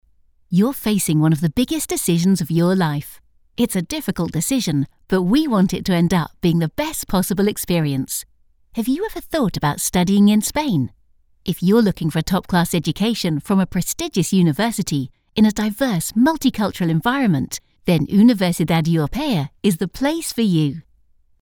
Female
British English (Native)
Assured, Confident, Engaging, Friendly, Reassuring, Warm, Corporate, Natural
corporate.mp3
Microphone: Rode NT1-A
Audio equipment: Isovox 2 vocal booth, Focusrite Scarlett pre-amp